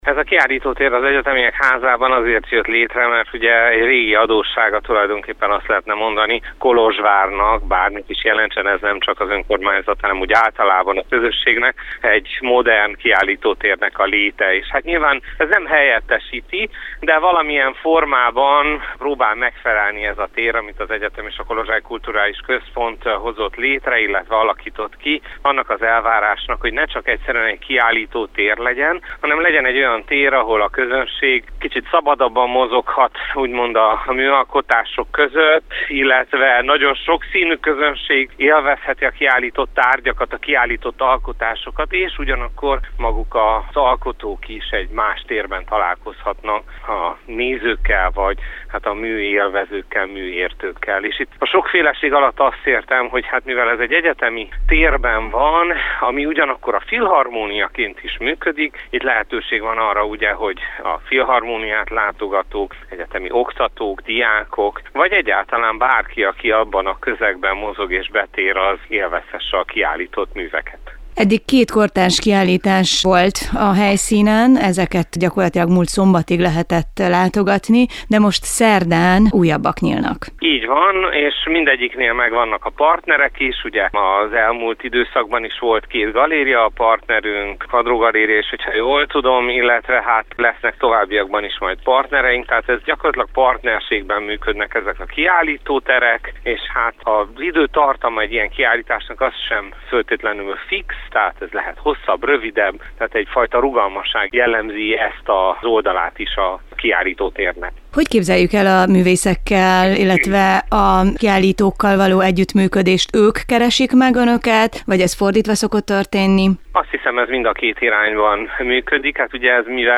beszélgettünk.